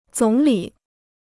总理 (zǒng lǐ): premier; prime minister.